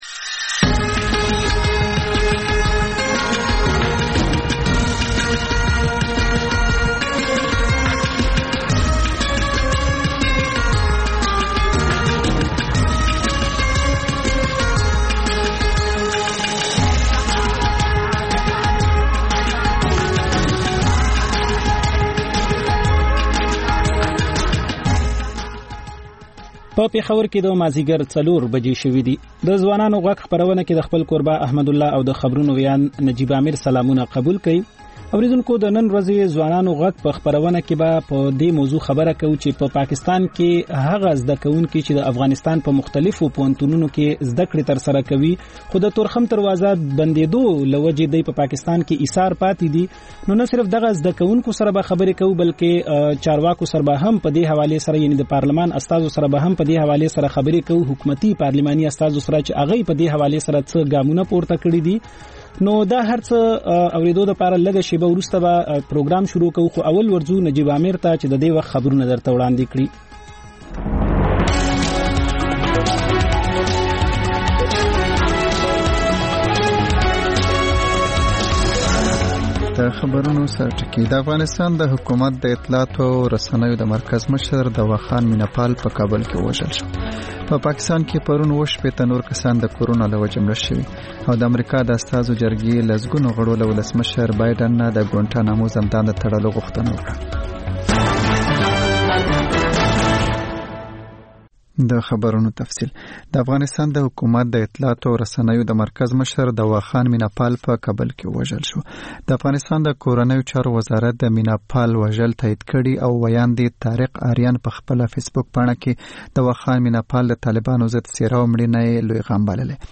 د خپرونې پیل له خبرونو کېږي، بیا ورپسې رپورټونه خپرېږي. ورسره اوونیزه خپرونه/خپرونې هم خپرېږي.